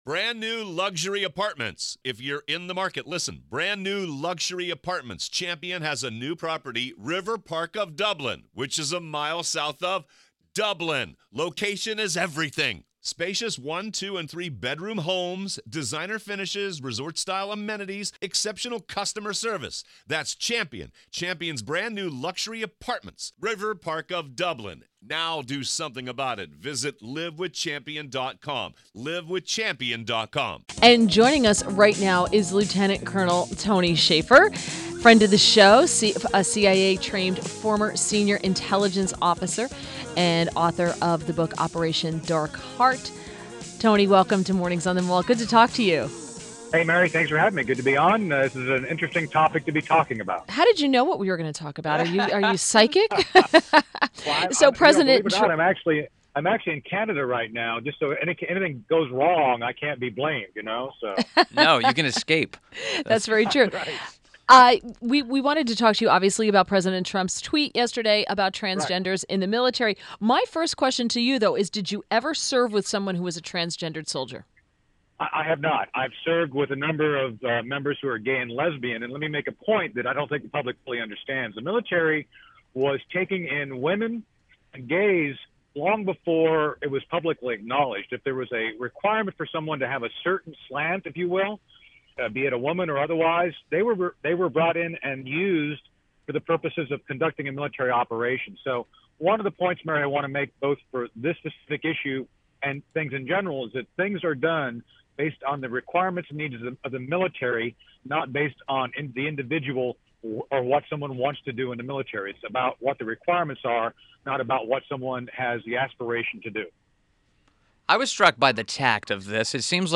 WMAL Interview - LT. COL. TONY SHAFFER - 07.27.17
INTERVIEW — LT. COL TONY SHAFFER – a CIA trained former senior intelligence officer and the New York Times bestselling author of ” Operation Dark Heart.”